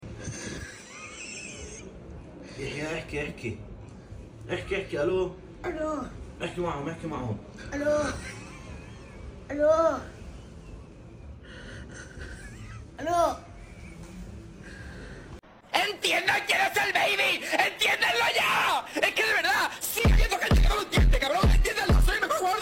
yelling